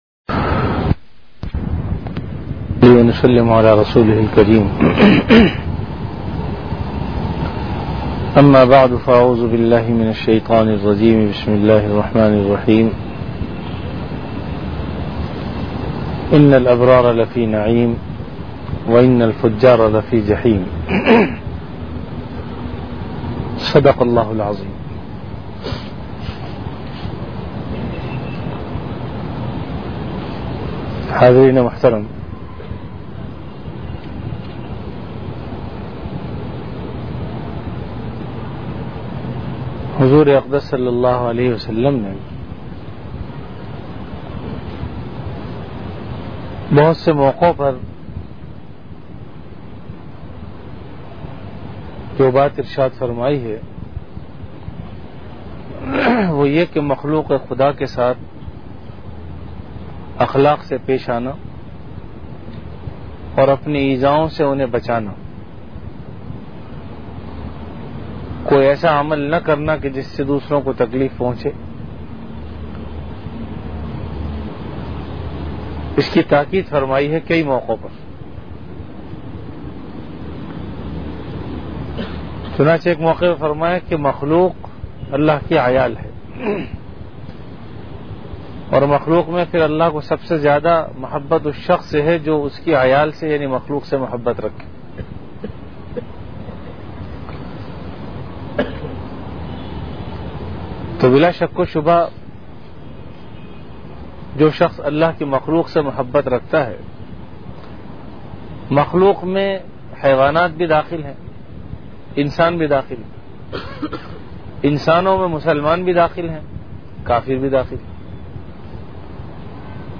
Bayanat · Jamia Masjid Bait-ul-Mukkaram, Karachi
Event / Time After Isha Prayer